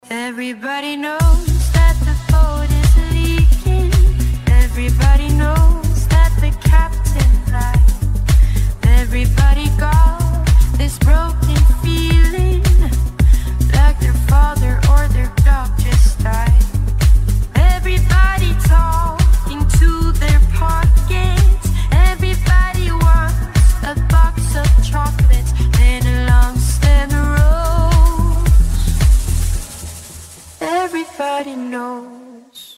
приятные женский голос
спокойные
Поп